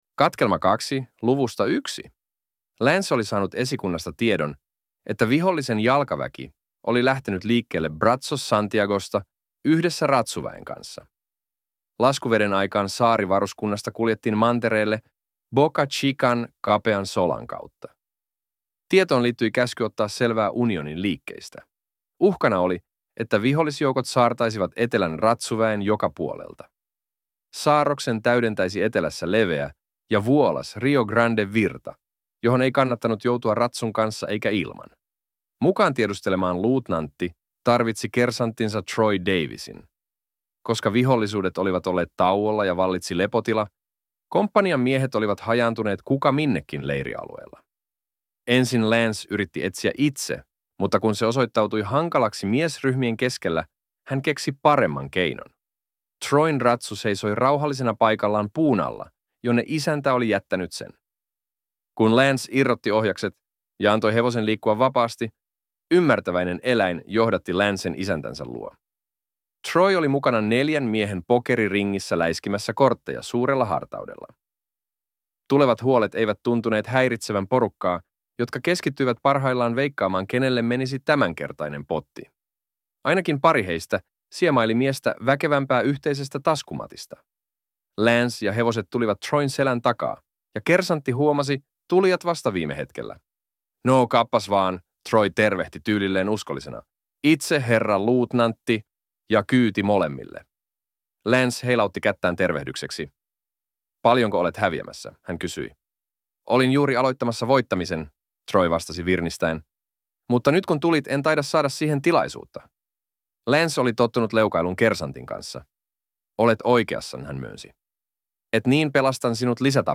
Audiokirja